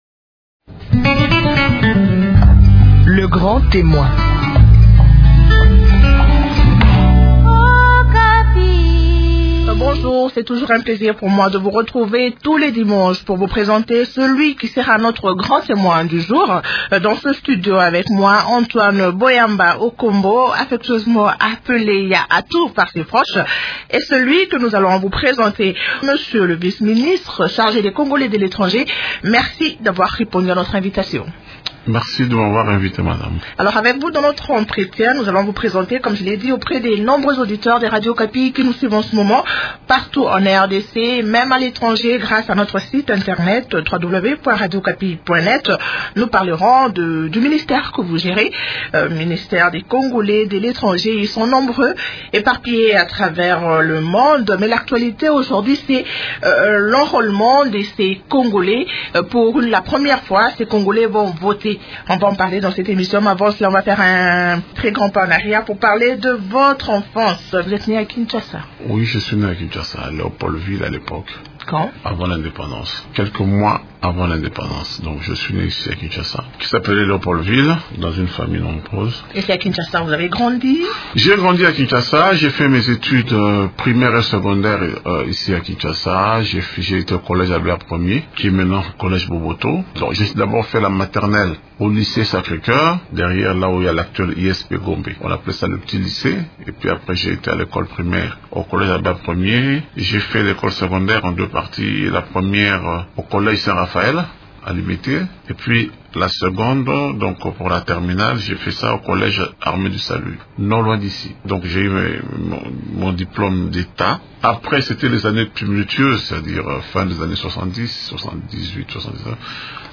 Antoine Boyamba, vice-ministre des Congolais de l’étranger, est l’invité du magazine Grand témoin cette semaine.